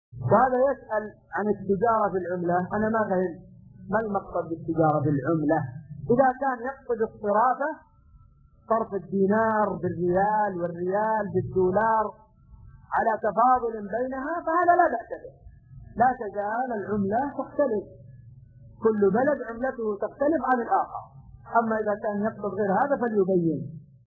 السؤال مقتطف كتاب الصيام من شرح زاد المستقنع .